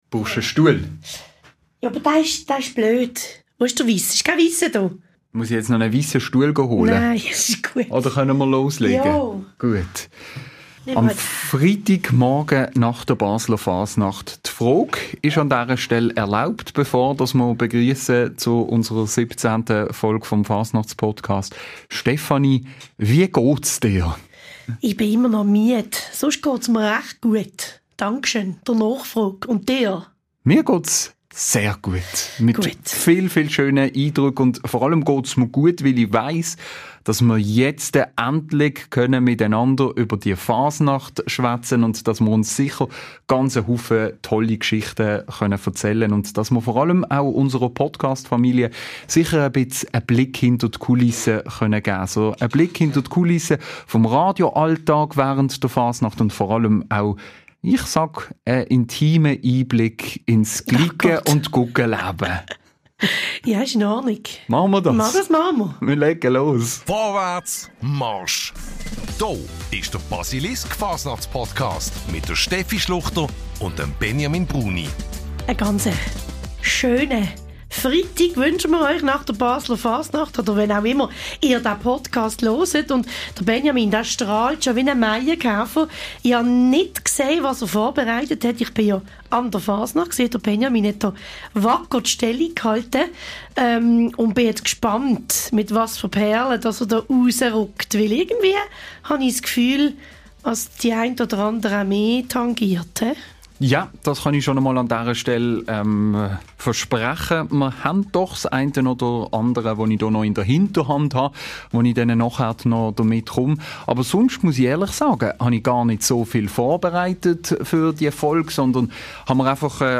Wie gewohnt in einer lockeren Runde und mit zahlreichen Geschichten, die einen Blick hinter die Kulissen des Cliquen- und Radioalltags ermöglichen.